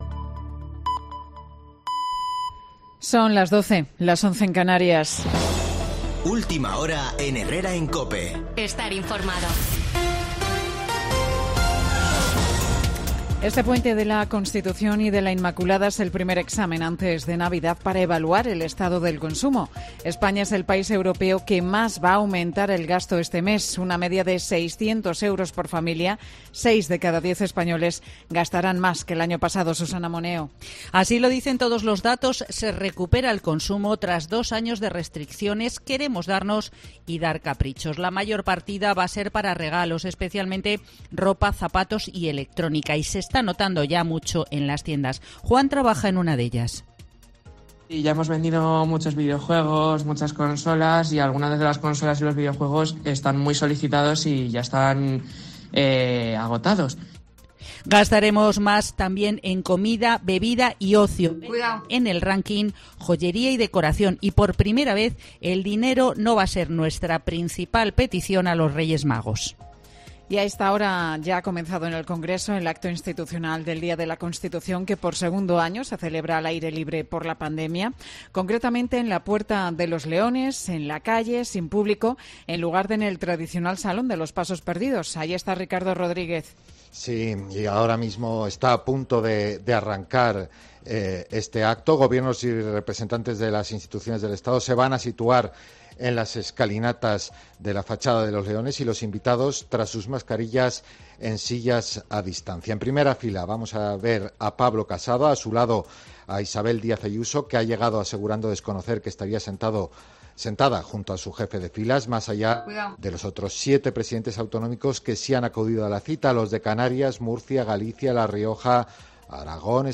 Boletín de noticias COPE del 6 de diciembre de 2021 a las 12.00 horas